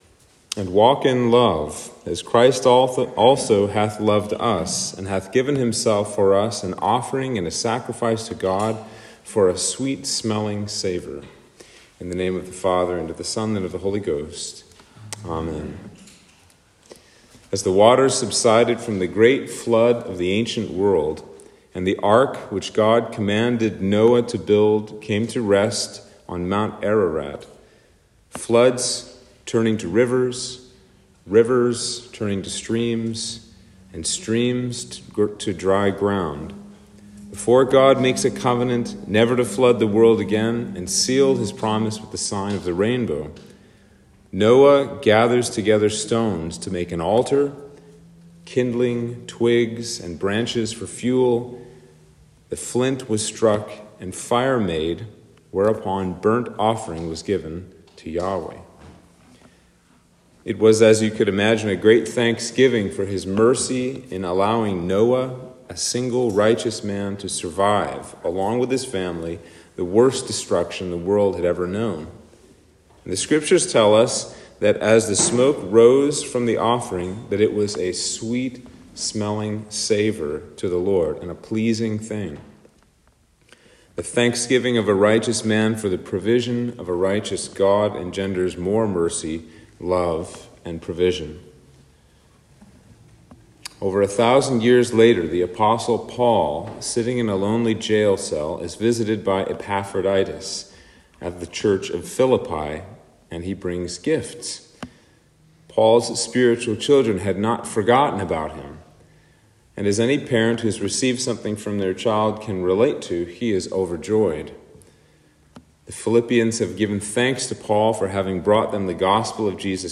Sermon for Lent 3 - 2022